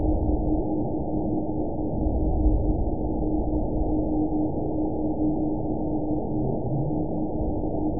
event 914171 date 04/29/22 time 23:26:48 GMT (3 years ago) score 7.24 location TSS-AB02 detected by nrw target species NRW annotations +NRW Spectrogram: Frequency (kHz) vs. Time (s) audio not available .wav